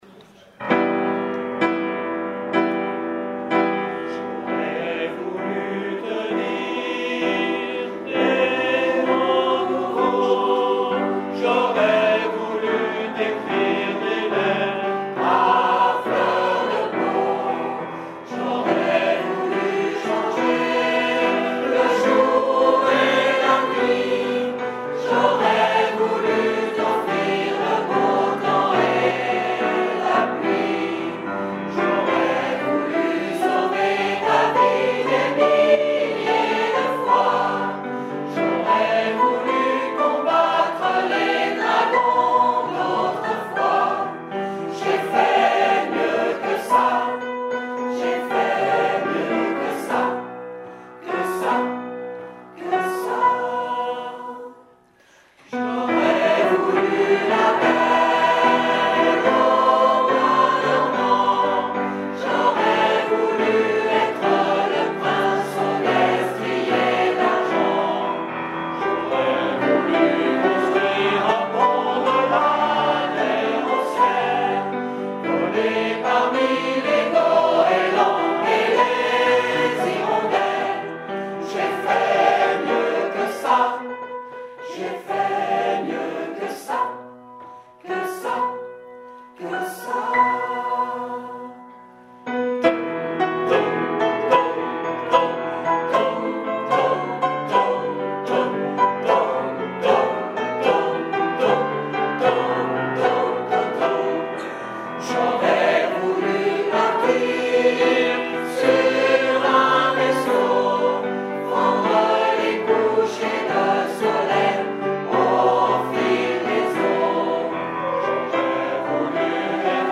Avertissement : Ce sont des enregistrements amateurs « live », preneurs de son inconnus… Aucune prétention commerciale bien entendu !
C’est au concert, en public et non en studio, que la musique vit et existe.